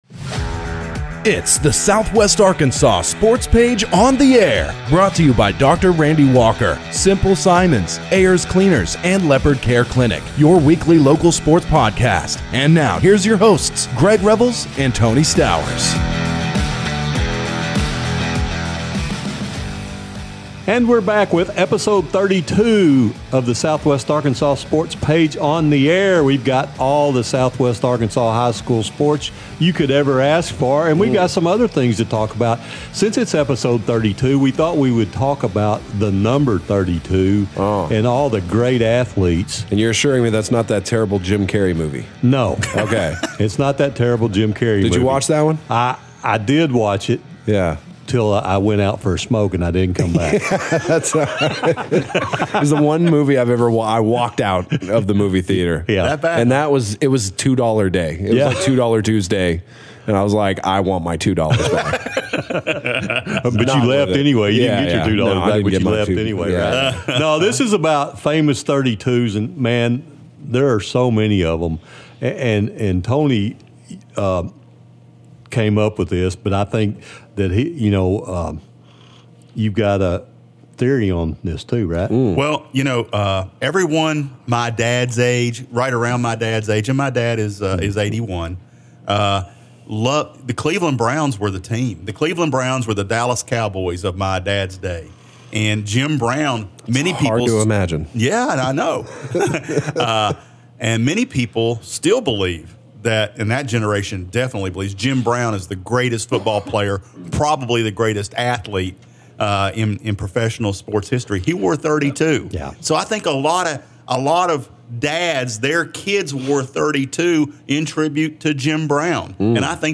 in studio to talk about the 2024 Solar Eclipse.-They boys preview the NCAA Basketball Tournaments.